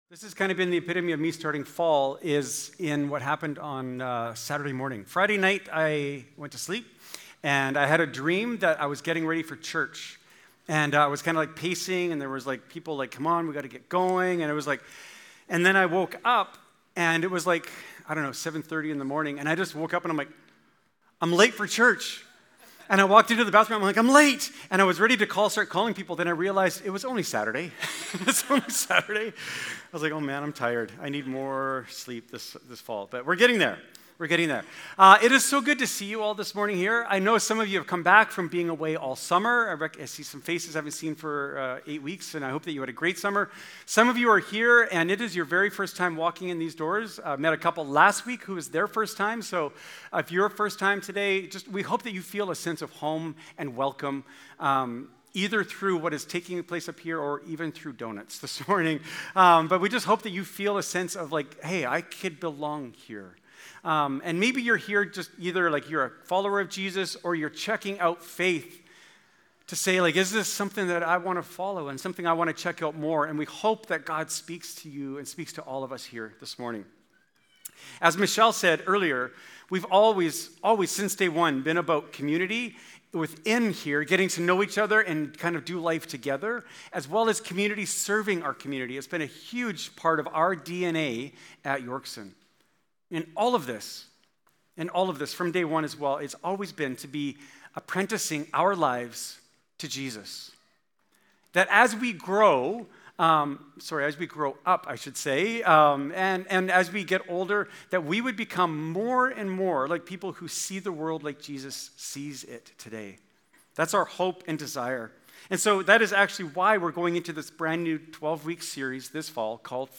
Yorkson Sermons | North Langley Community Church